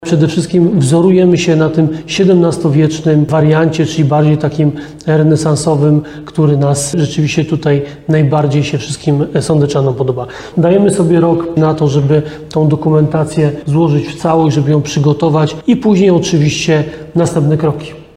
– mówi Ludomir Handzel, prezydent Nowego Sącza.